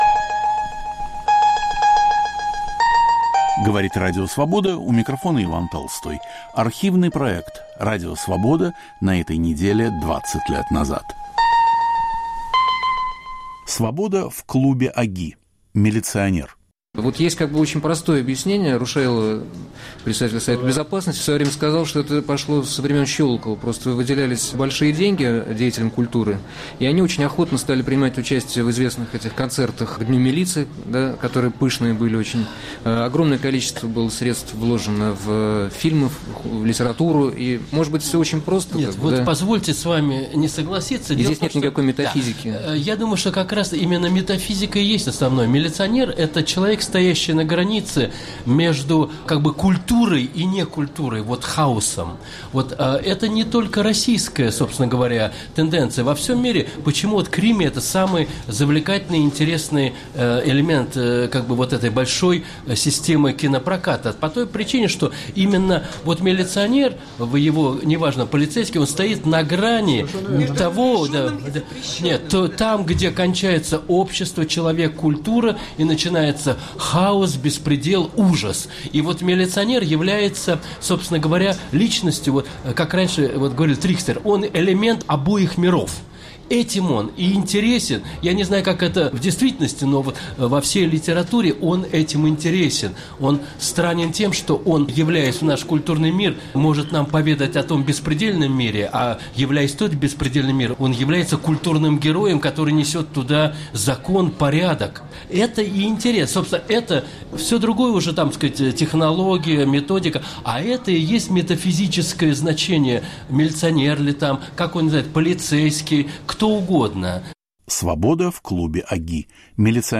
"Свобода" в клубе ОГИ. Милиционер